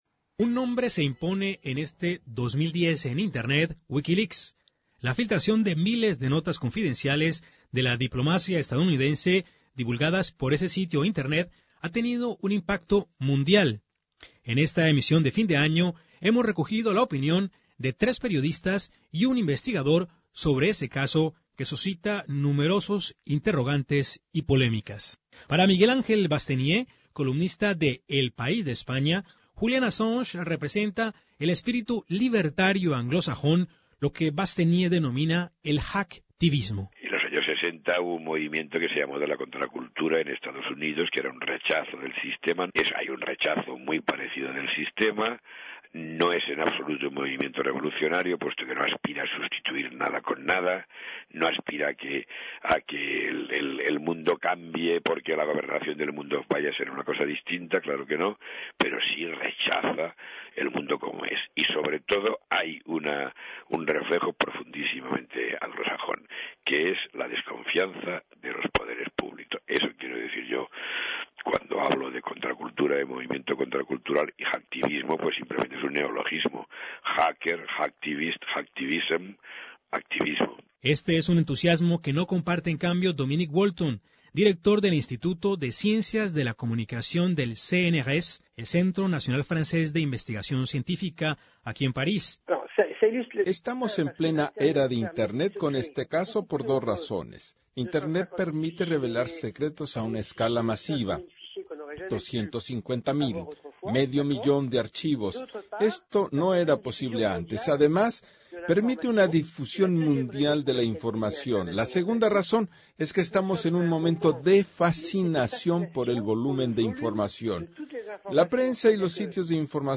La filtración de cables confidenciales de la diplomacia norteamericana divulgadas por ese sitio tuvo un impacto mundial. Escuche el informe de Radio Francia Internacional.